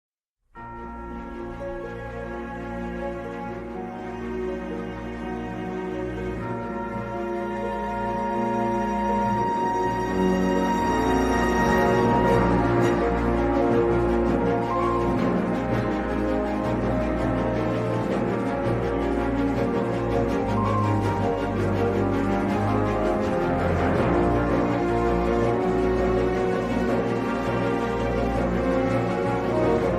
Boss Theme
Source Ripped from the official soundtrack